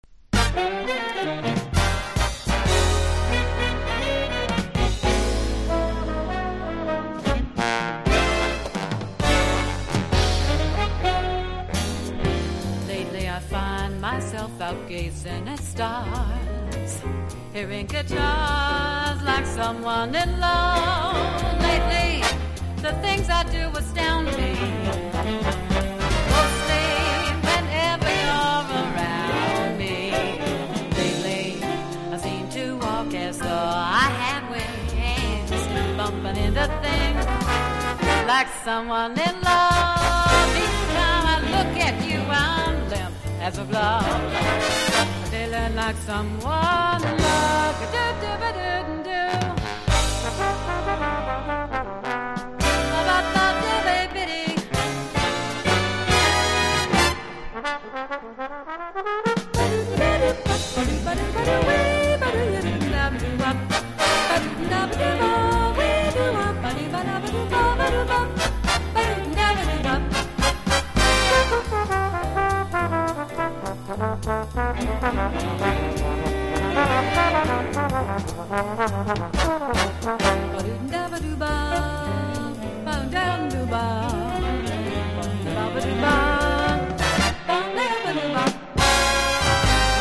のブラジリアン・カヴァーを収録した人気盤。